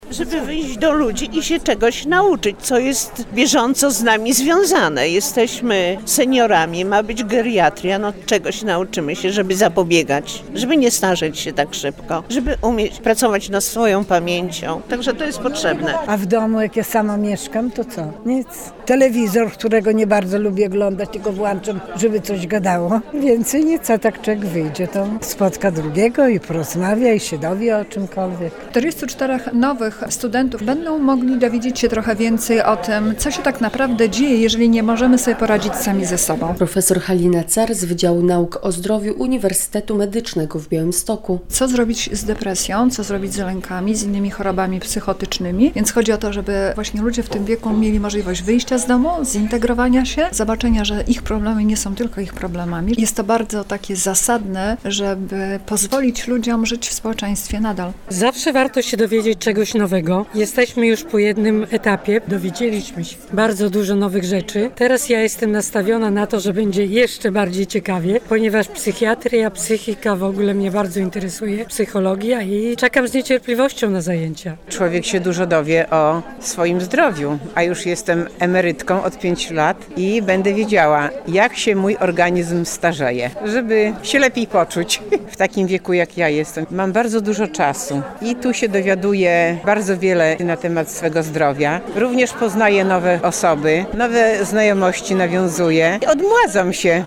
Na wydziale odbyła się uroczysta inauguracja zajęć.
Relacja